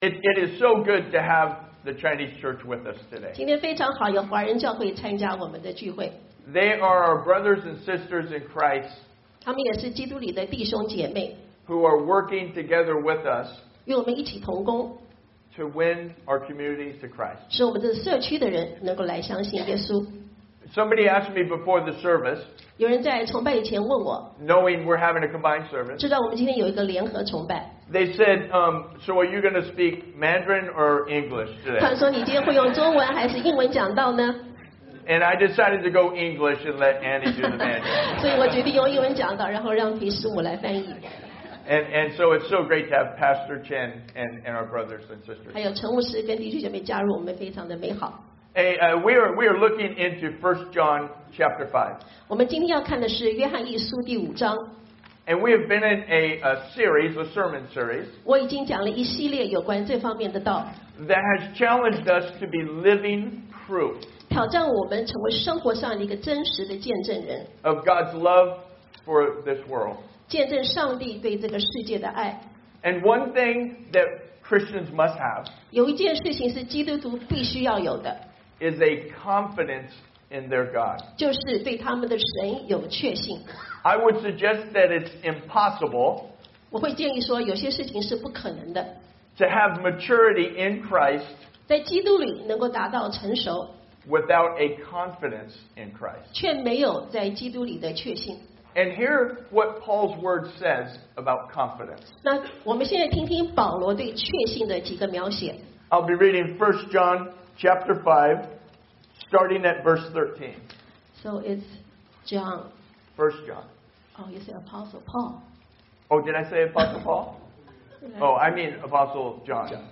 Passage: I John 5:13-21 Service Type: Sunday AM